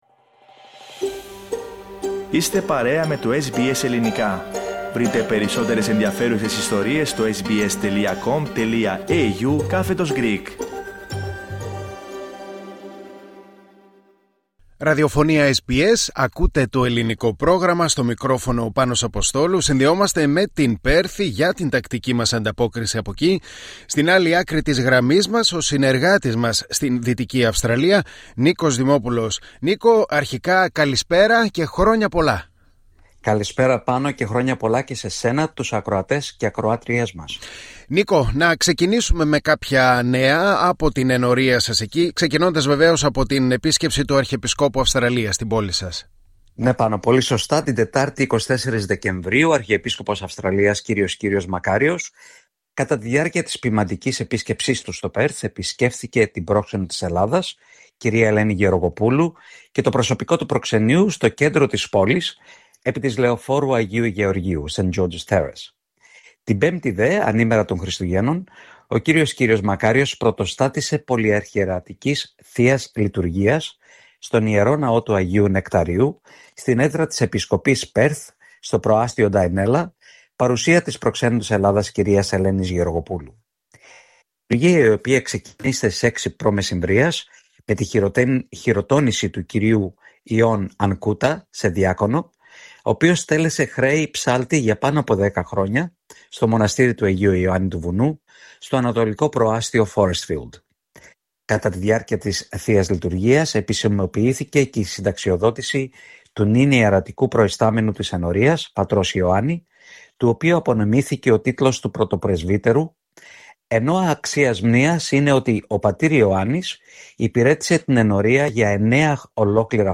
Ακούστε την ανταπόκριση από την Πέρθη